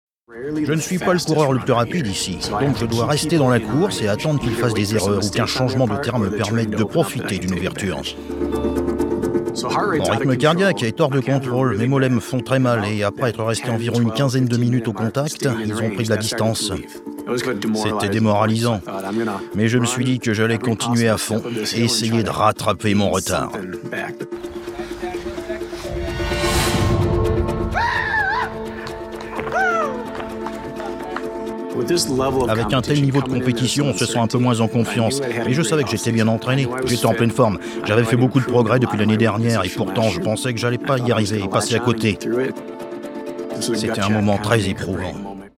VOIX OFF – VOICE-OVER: reportage « Spartan Race »